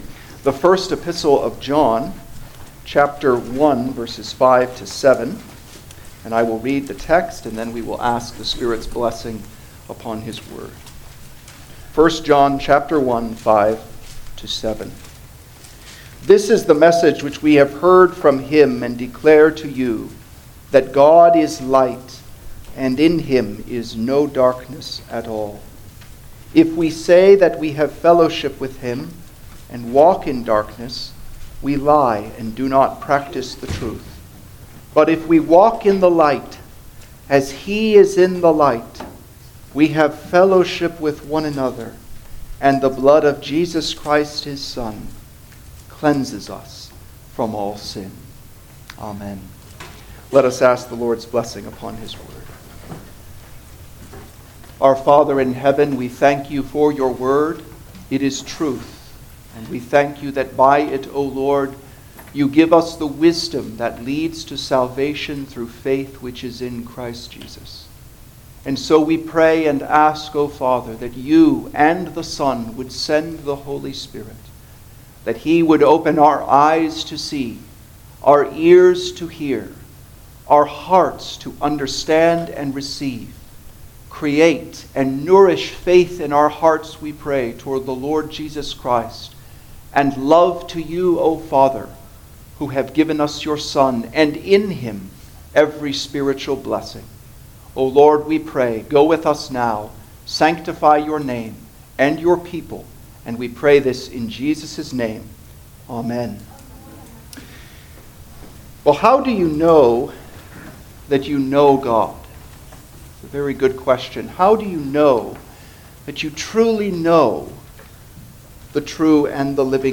Guest Preachers Passage: 1 John 1:5-7 Service Type: Sunday Morning Service Download the order of worship here .